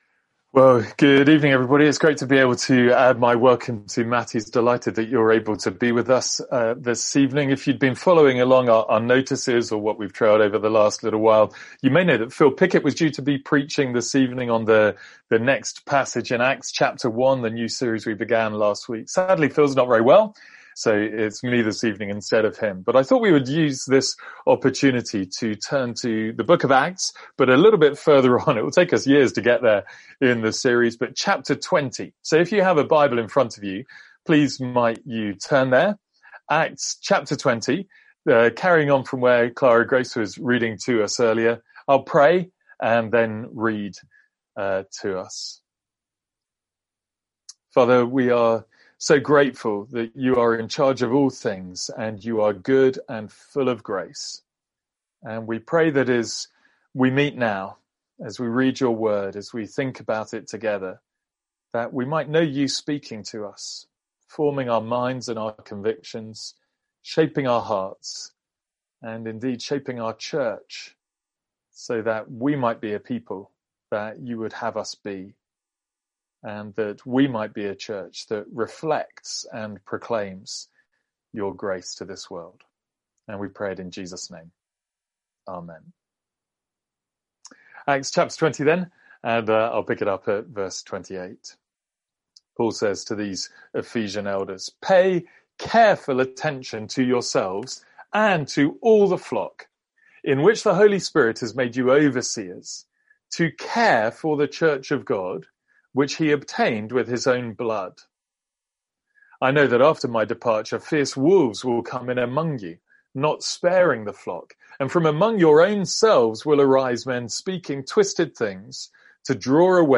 A one off sermon from Acts.